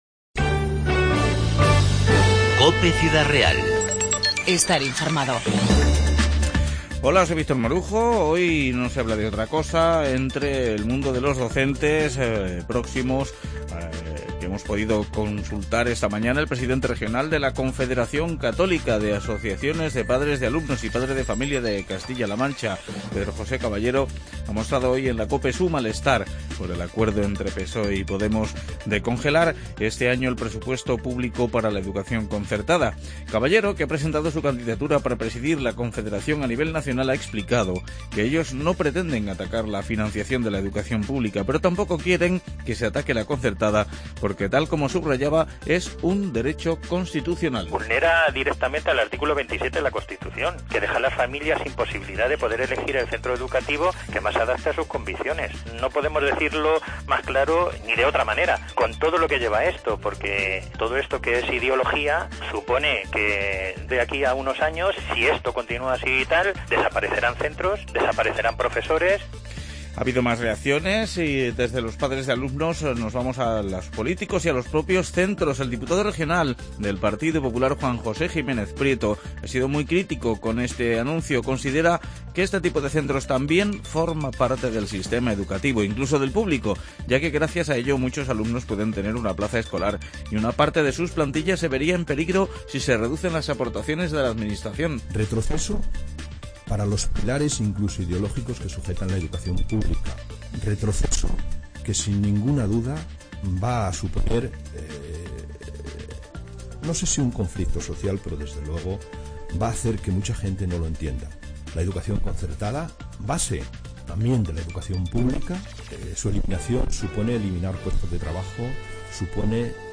INFORMATIVO 9-2-15